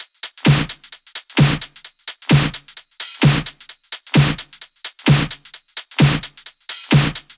VFH2 130BPM Comboocha Kit 5.wav